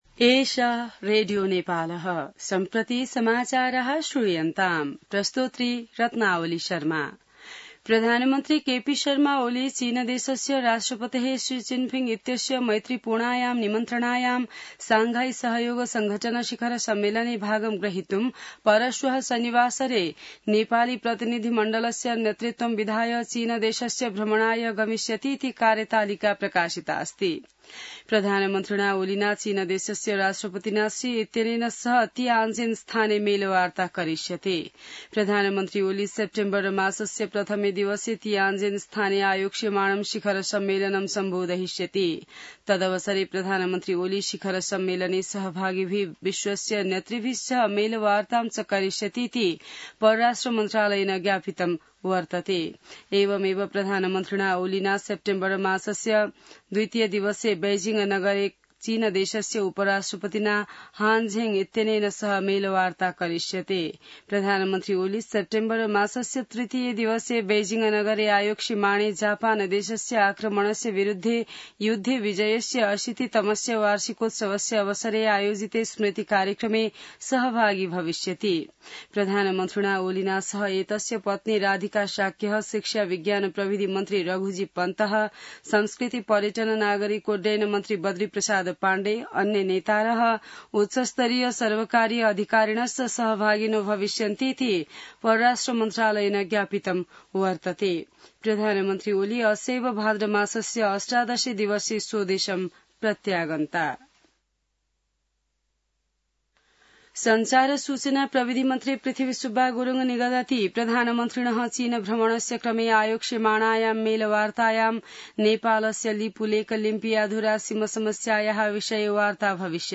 संस्कृत समाचार : १२ भदौ , २०८२